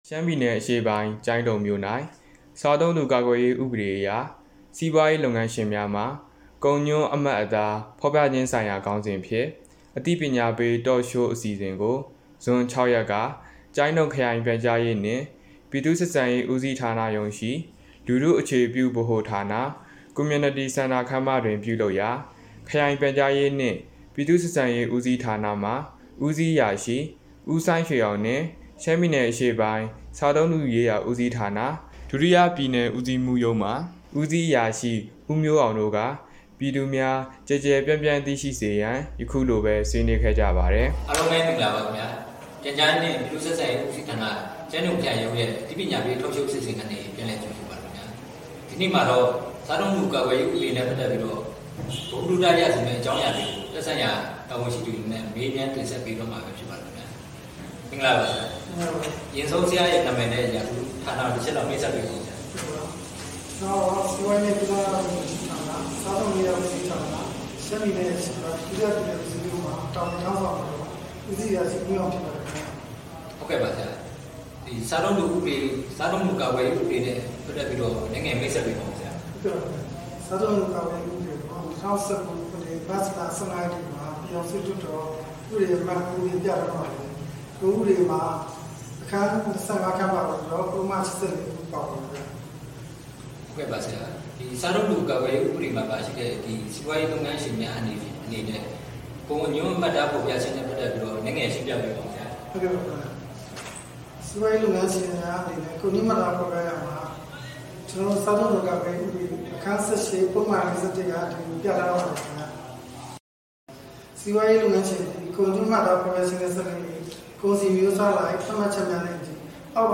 ကျိုင်းတုံမြို့၌ စားသုံးသူကာကွယ်ရေးဥပဒေအရ စီးပွားရေးလုပ်ငန်းရှင်များမှ ကုန်ညွှန်းအမှတ်အသားဖော်ပြခြင်းဆိုင်ရာ Talk Show ပြုလုပ်
Infotainment